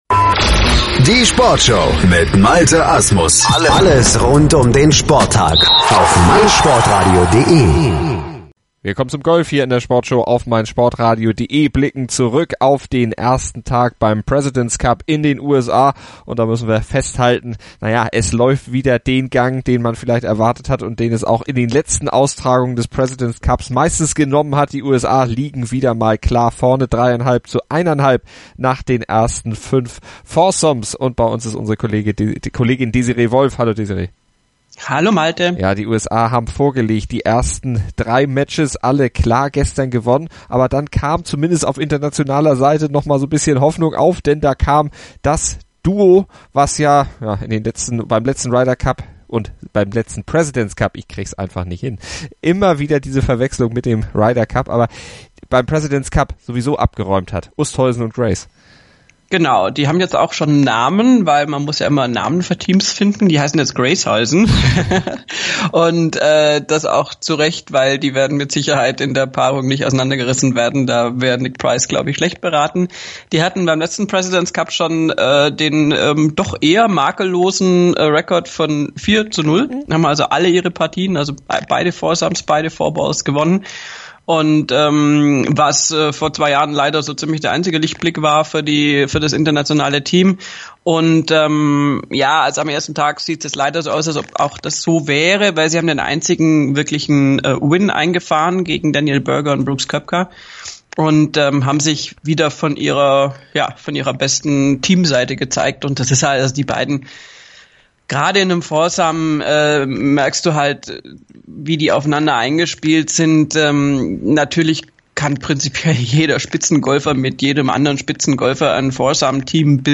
Der Deutsche liegt als geteilter 13. gut im Rennen und äußert sich im Interview sehr zufrieden mit seinem ersten Tag.